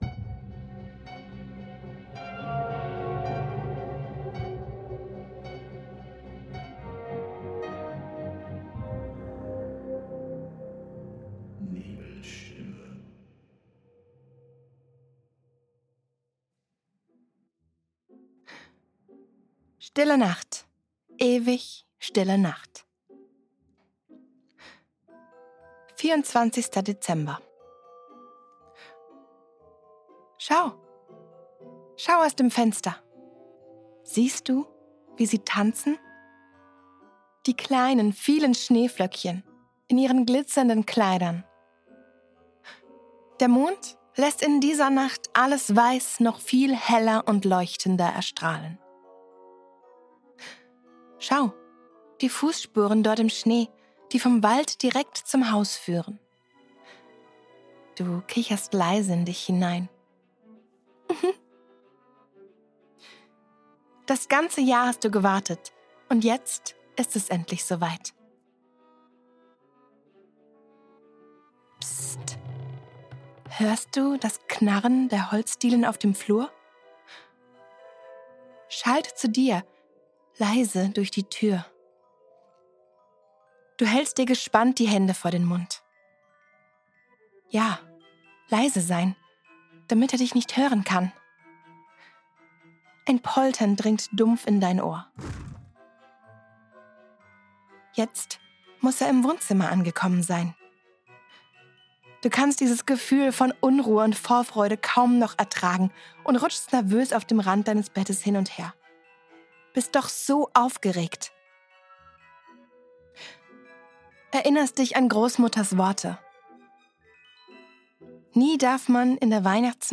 Diese Geschichte war eine meiner früheren Aufnahmen — und bekommt
Intro & Outro